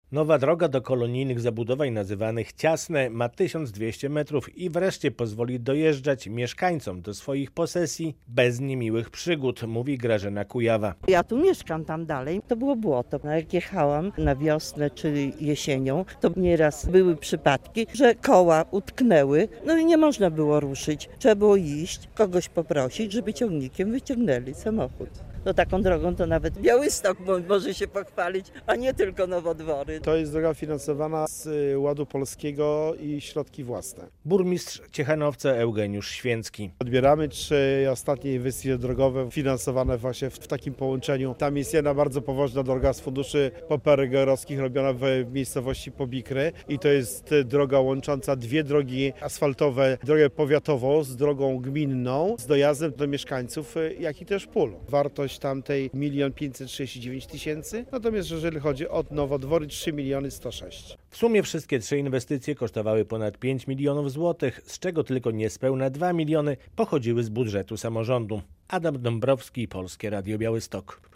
Gmina Ciechanowiec z nowymi drogami - relacja